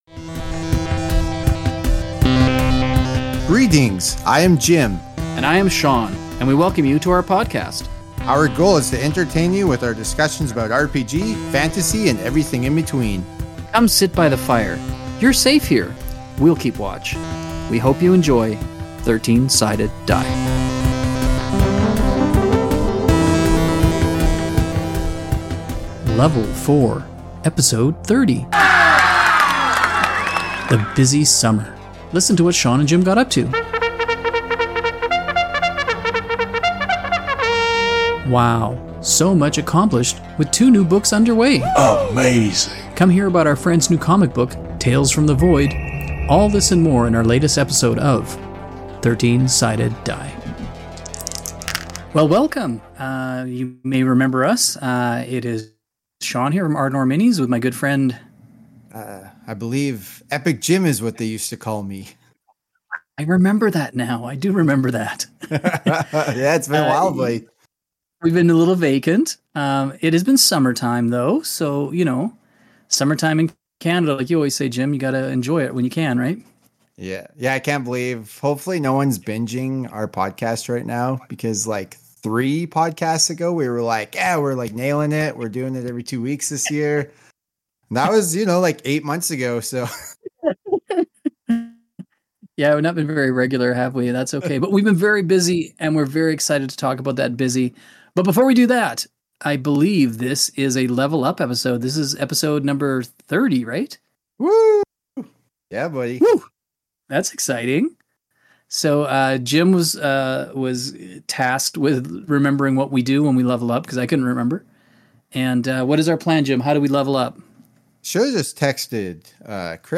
Cheering sound.m4a
US Military Soldier Shouting "Whoo!"
Spooky Ambiance #1